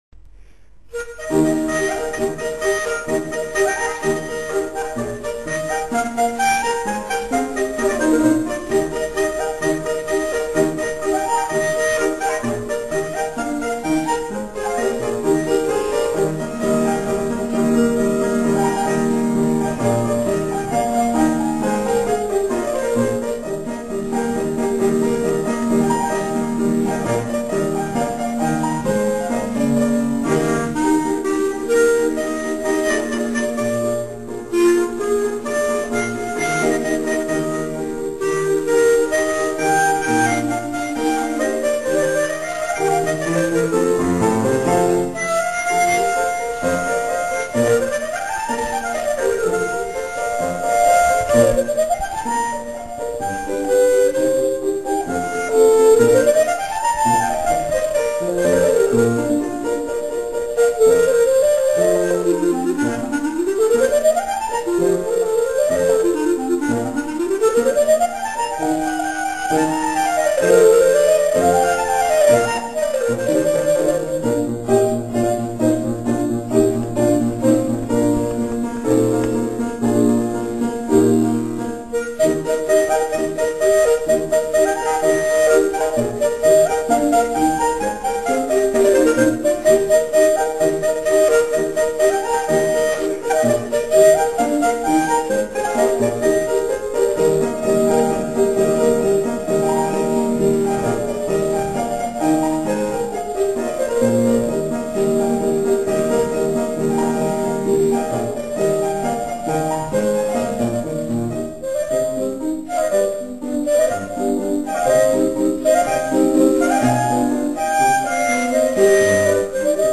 27. komorní koncert na radnici v Modřicích
Ukázkové amatérské nahrávky WMA:
Rondo Es dur, klarinet
klavír